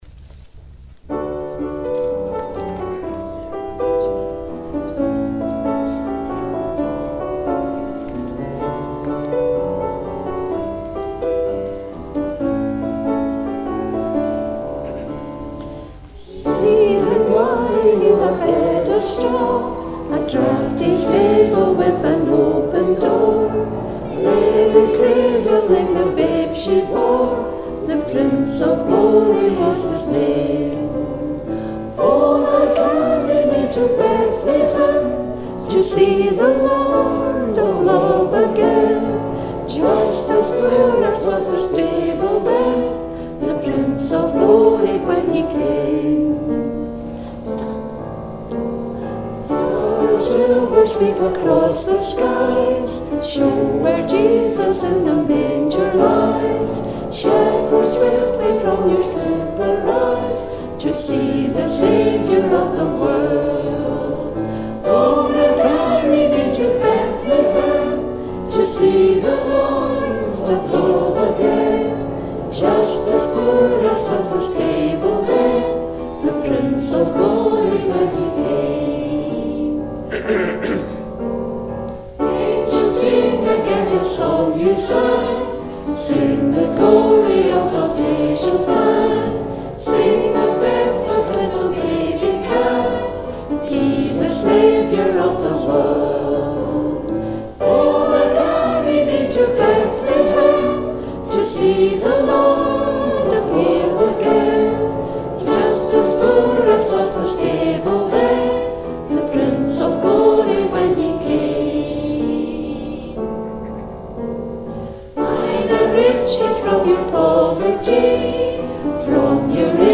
Hymn 310 'See him lying in a bed of straw'.